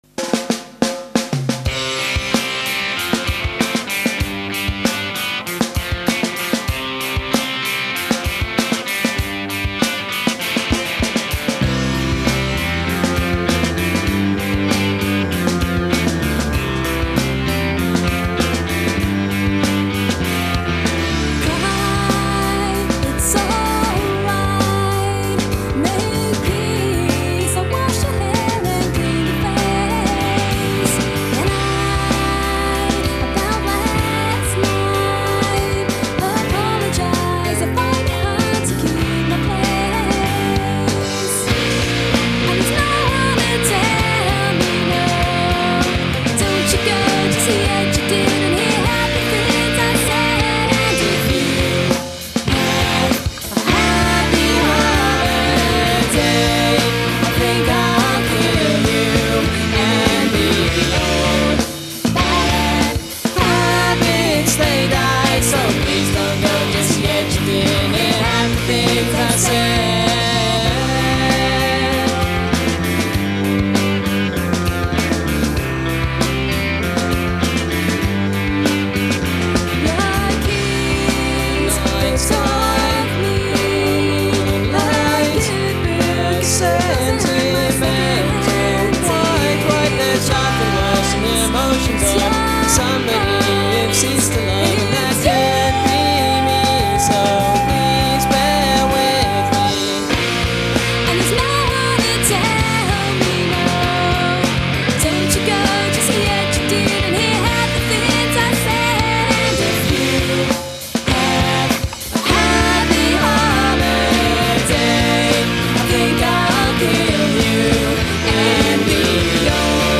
Studio Recordings
Recorded at Chamber Studios, Edinburgh